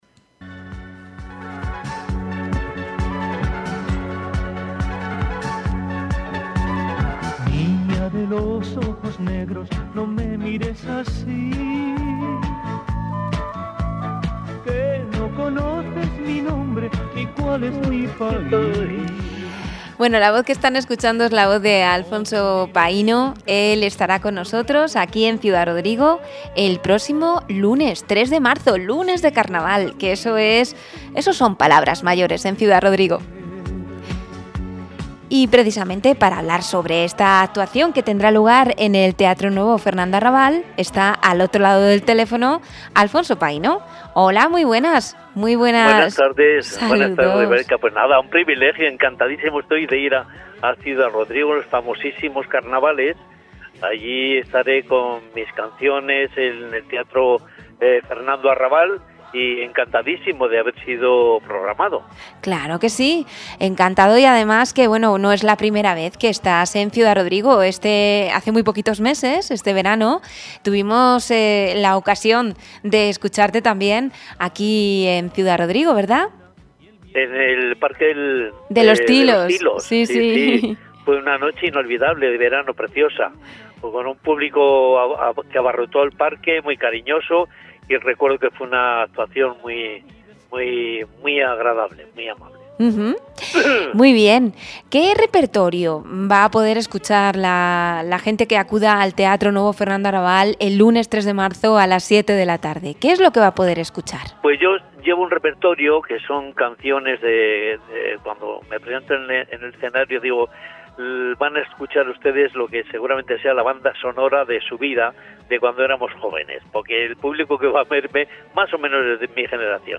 (AUDIO) Entrevista